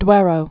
(dwĕrō)